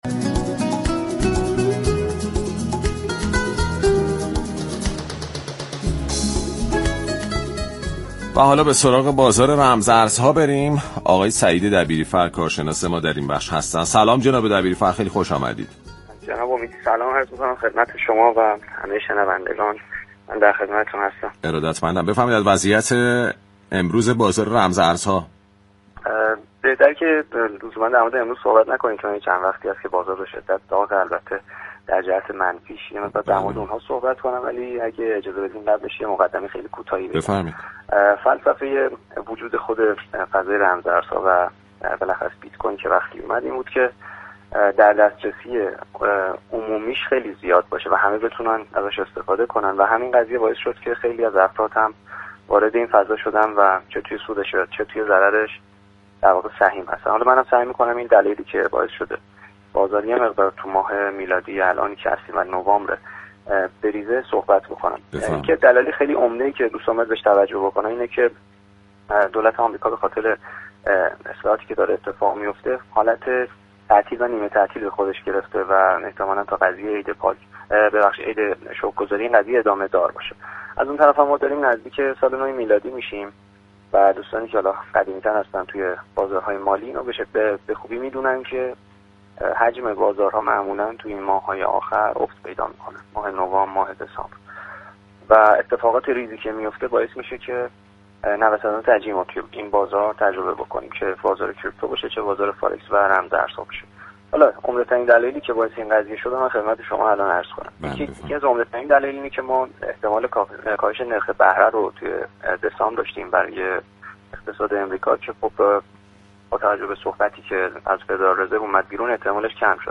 كارشناس بازار رمزارزها در گفتگو با رادیو تهران با اشاره به نوسانات اخیر بیت‌كوین و سایر دارایی‌های دیجیتال اعلام كرد كه تحولات سیاسی و اقتصادی آمریكا در كنار كاهش حجم معاملات پایان سال، موجب تغییرات شدید در بازار شده است.